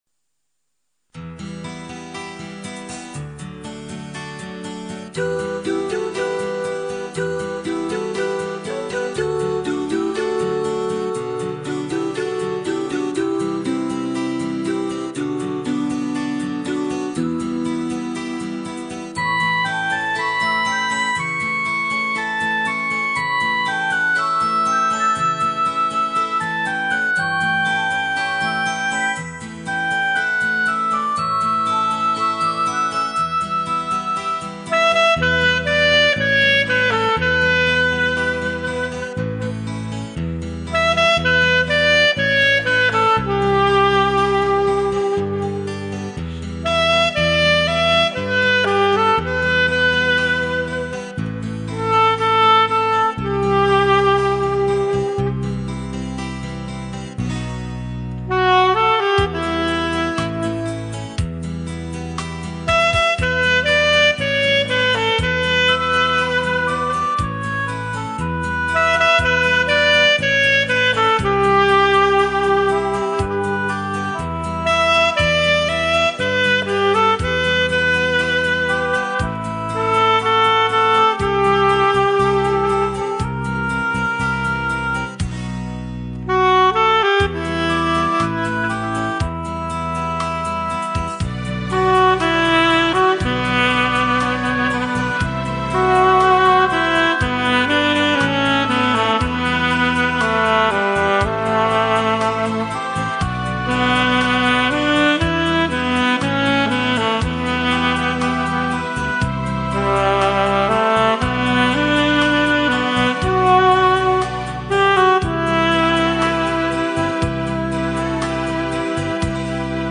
萨克斯传奇 倾情吹出荡气回肠